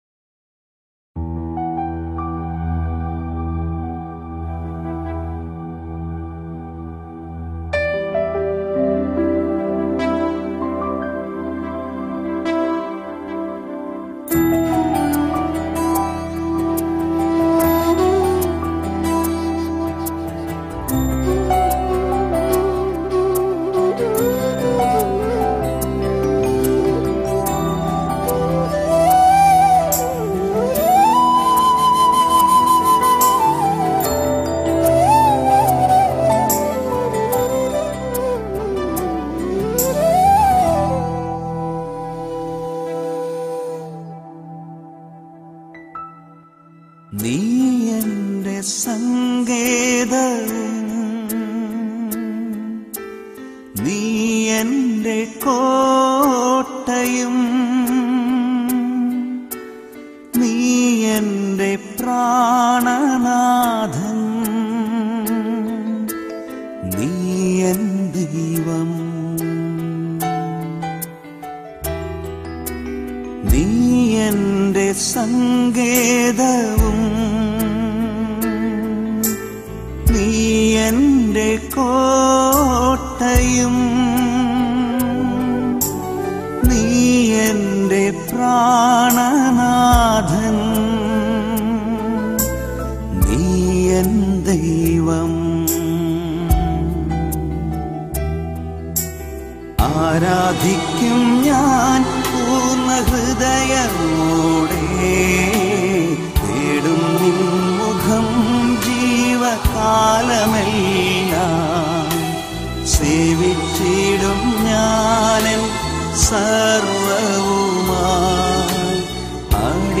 Devotional Songs - NEE ENTE SANGETAVUM
NEE-ENTE-SANGETAVUM-MALAYALAM-DEVOTIONAL-SONG.mp4.mp3